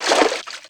MISC Water, Splash 02.wav